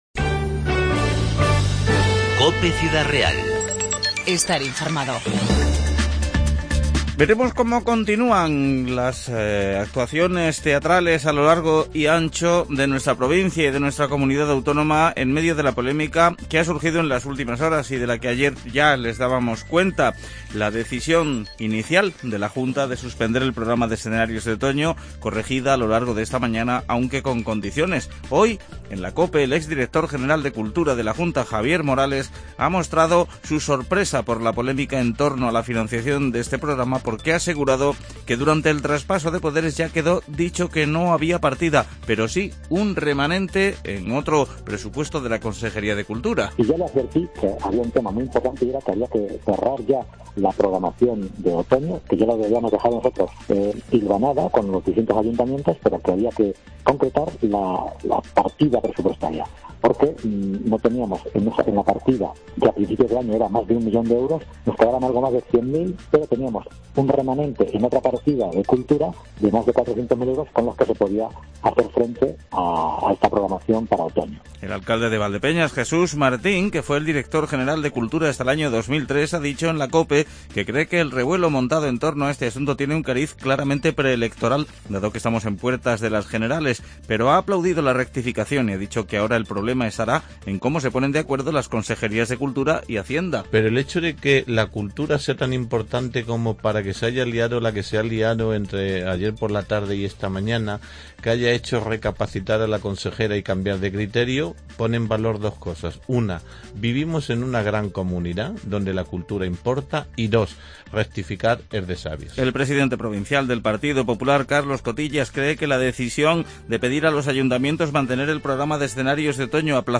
INFORMATIVO MEDIODÍA 9-10-15